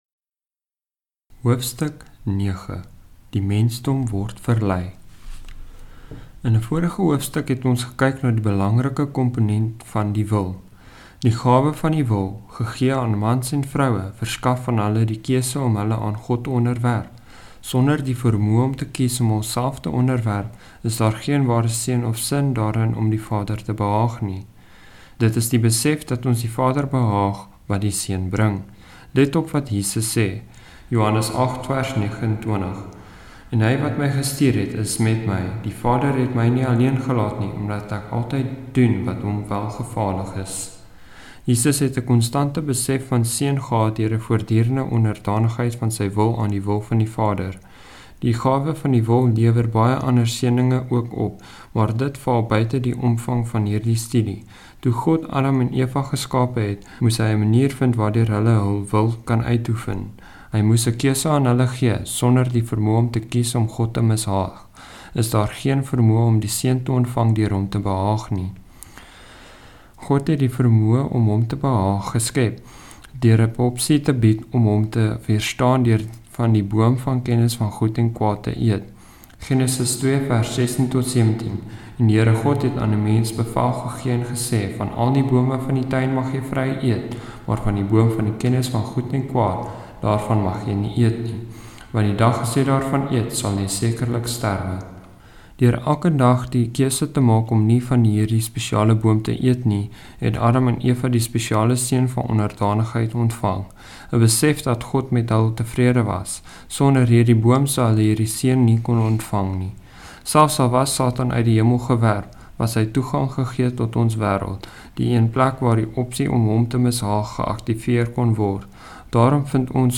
Audio Boeke